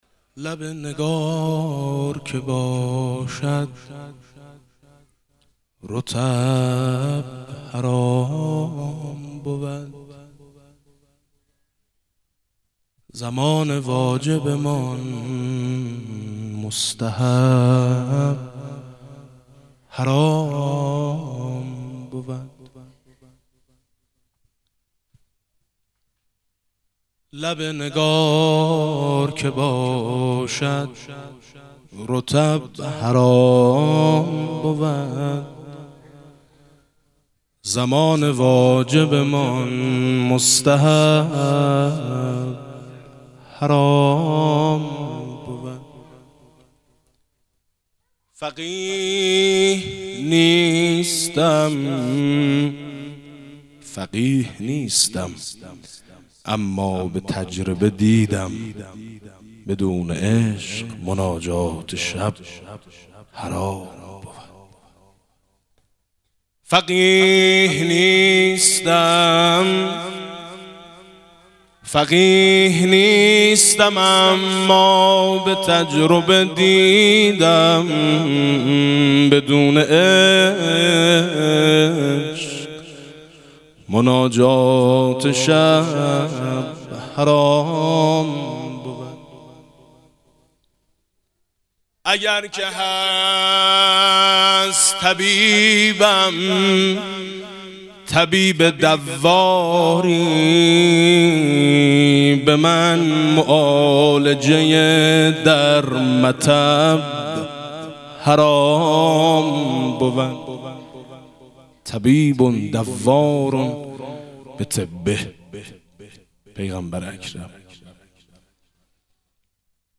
گلچین مبعث خوانی پنج مداح سرشناس کشور
تهران- الکوثر: همزمان با فرارسیدن جشن مبعث حضرت خاتم الانبیاء (ص) فایل صوتی گلچین مولودی با نوای مداحان اهل بیت (ع) را می شنوید.